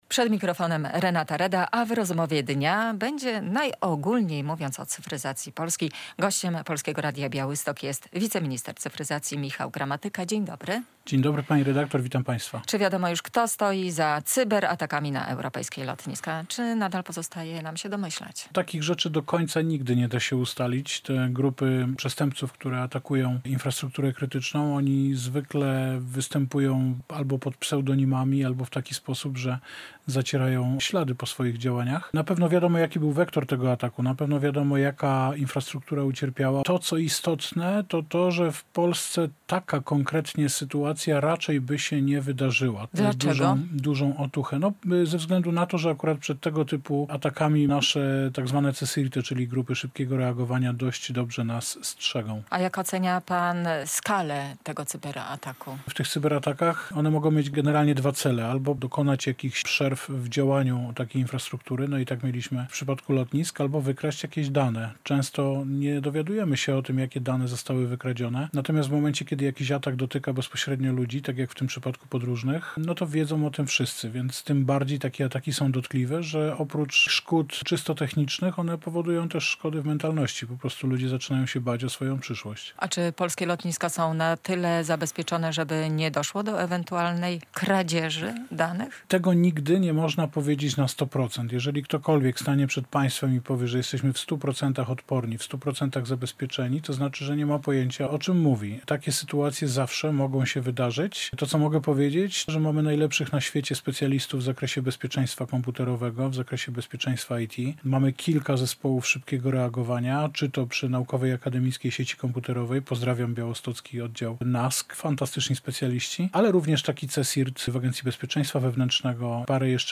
- W 100 procentach odporni na cyberataki nigdy nie będziemy. Jeżeli ktokolwiek powie, że jest inaczej, to znaczy, że nie ma pojęcia, o czym mówi - mówił w Polskim Radiu Białystok wiceminister cyfryzacji Michał Gramatyka.